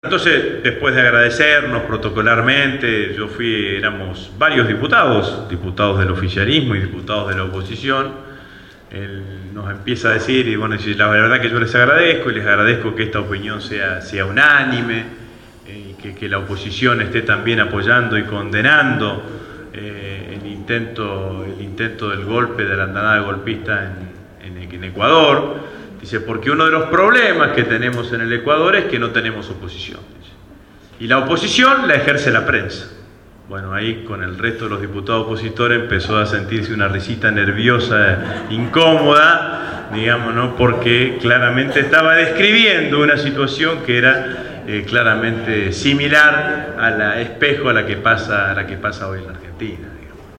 El último 4 de Octubre, organizado por la agrupación «Todas con Cristina«, estuvo presente en el espacio de la Radio Gráfica Agustín Rossi, Presidente del Bloque del FPV en la cámara baja.
Casi dos horas de charla-debate sirvieron para dejar satisfecha a la concurrencia, responder inquietudes y plantear nuevos desafios